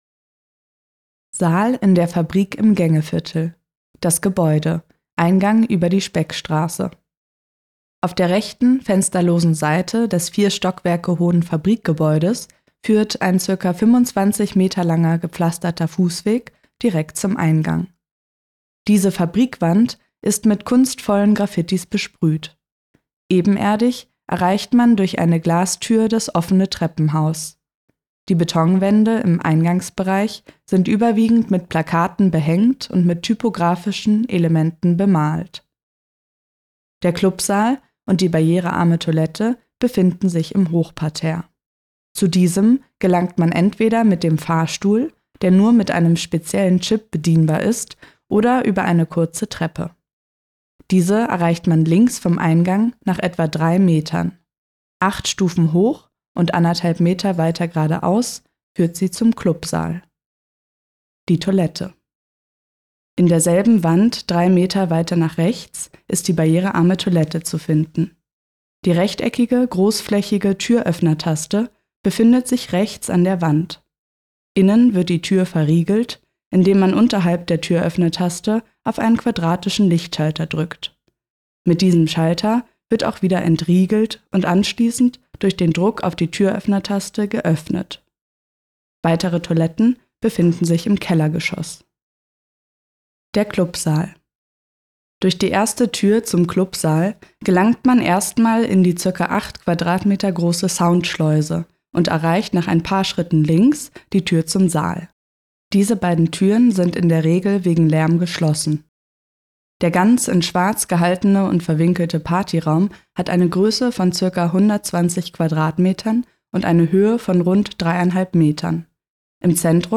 Hier in diesem Audio kannst du die 5 minütige Beschreibung anhören, etwas weiter unten befindet sich der ganze Text.